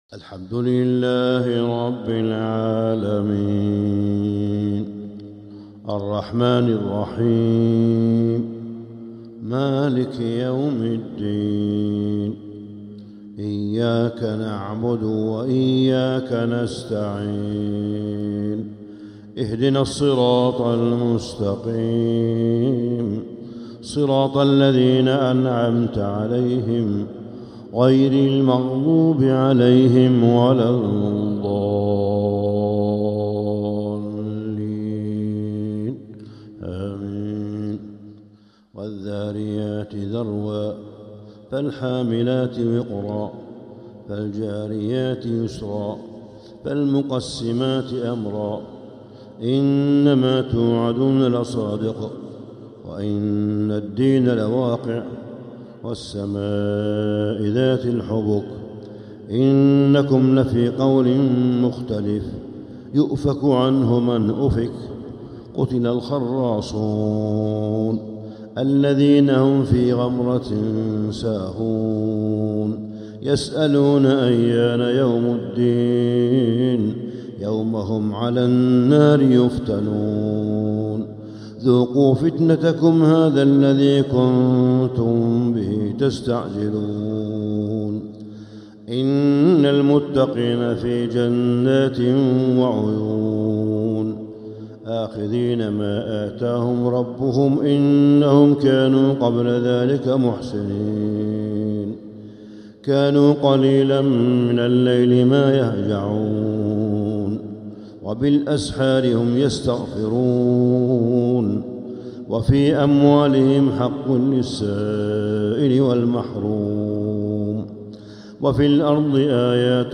النور التام لفروض المسجد الحرام 🕋 من 22 رمضان إلى 29 رمضان 1446هـ ( الحلقة 50 ) > إصدارات النور التام لفروض المسجد الحرام 🕋 > الإصدارات الشهرية لتلاوات الحرم المكي 🕋 ( مميز ) > المزيد - تلاوات الحرمين